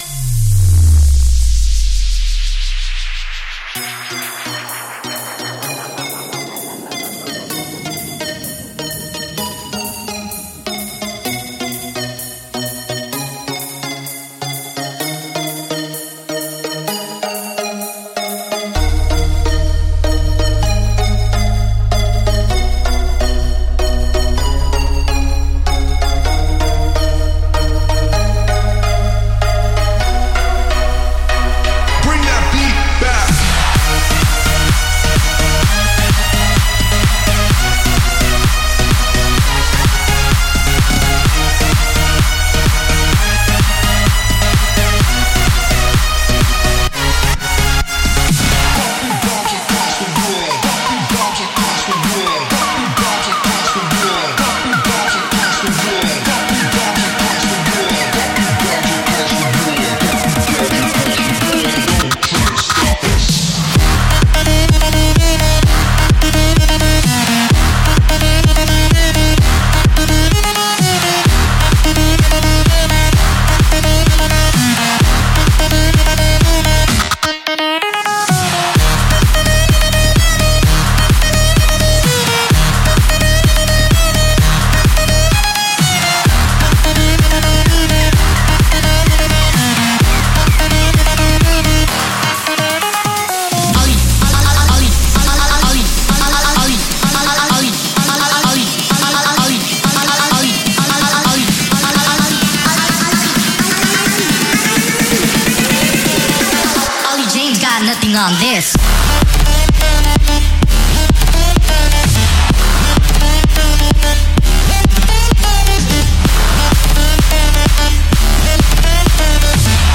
每种声音均已创建，可为您提供所需的震撼人心的声音，这样您就可以减少花费在声音上的时间，而将更多的精力花在实现想法上